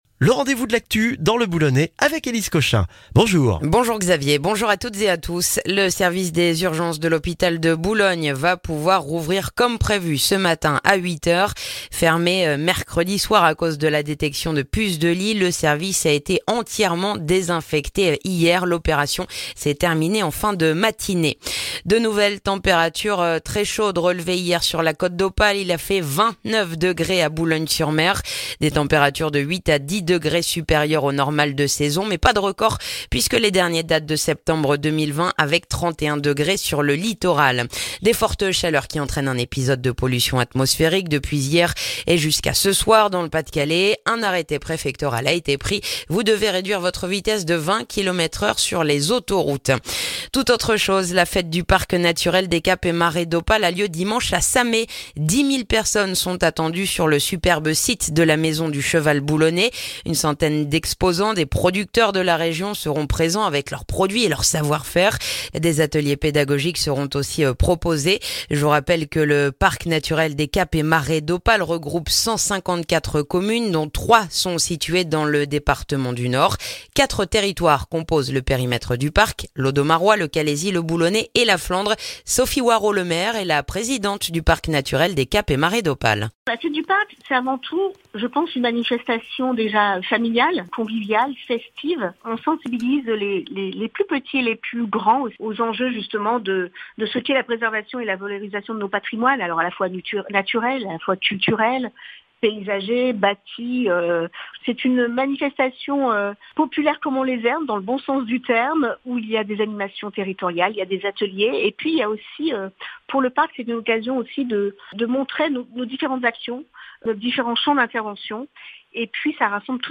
Le journal du vendredi 8 septembre dans le boulonnais